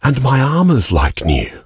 new_armor.ogg